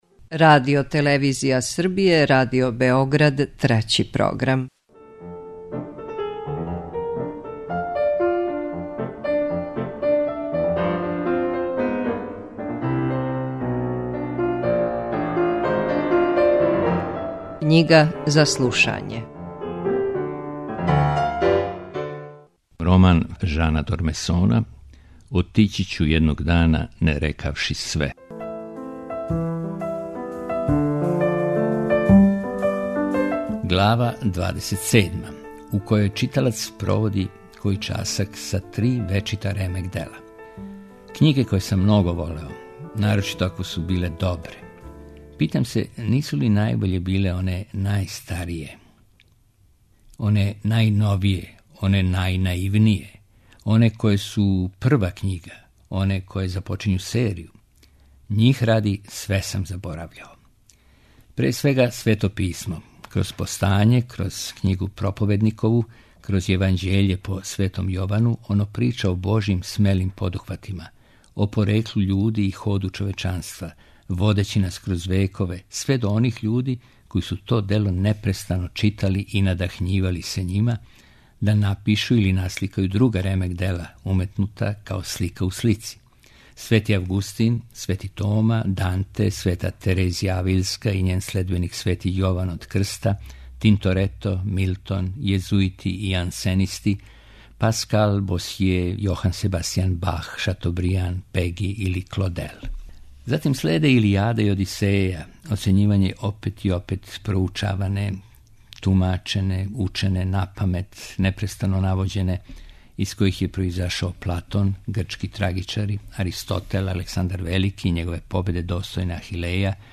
преузми : 4.82 MB Књига за слушање Autor: Трећи програм Циклус „Књига за слушање” на програму је сваког дана, од 23.45 сати.